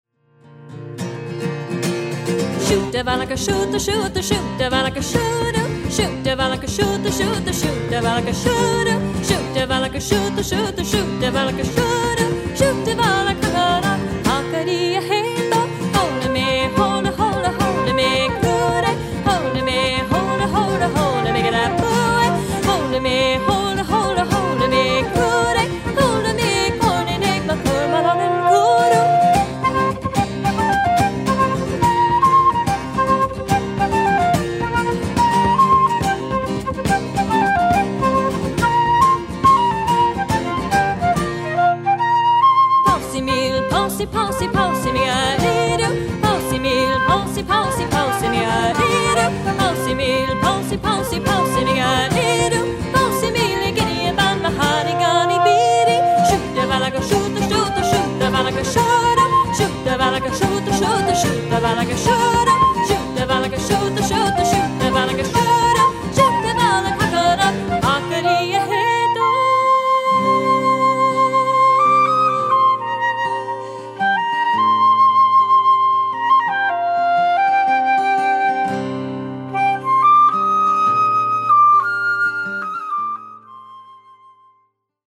Celtic music